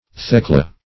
Thecla \Thec"la\, n.